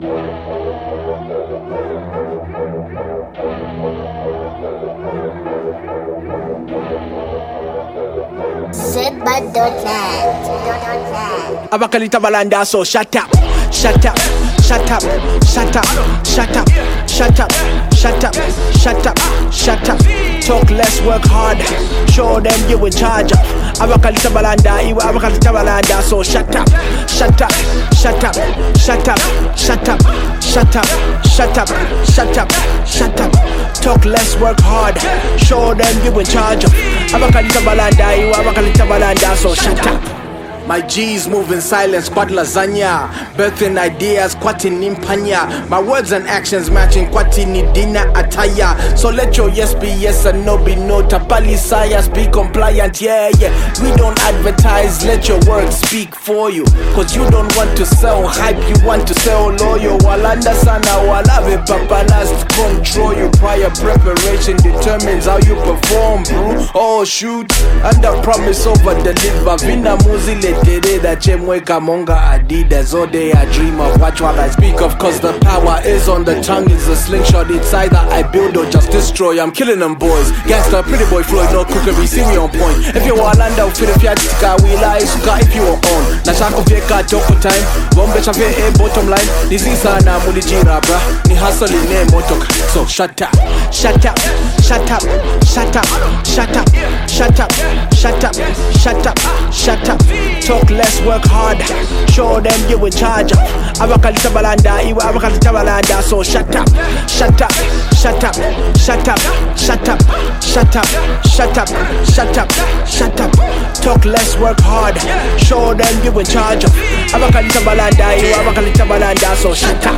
It’s straightforward, catchy, and made to be felt.